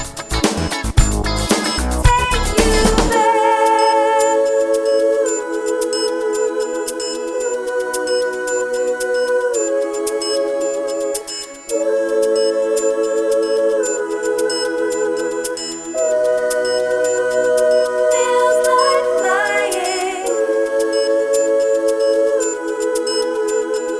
One Semitone Down Pop (1980s) 5:29 Buy £1.50